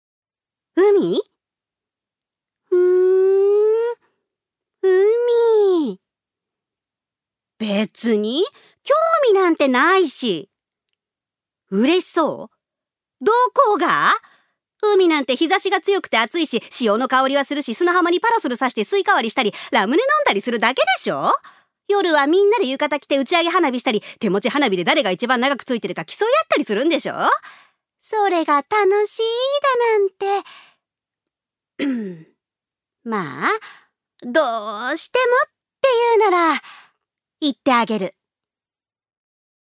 女性タレント
音声サンプル
セリフ２